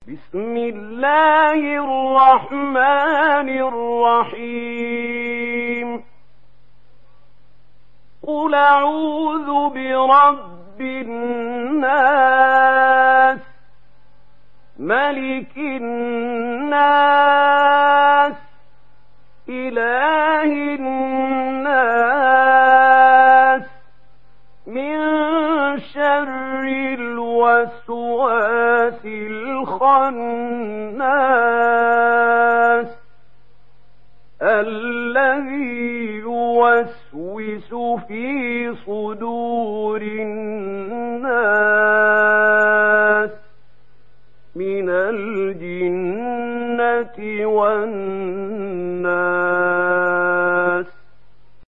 دانلود سوره الناس mp3 محمود خليل الحصري (روایت ورش)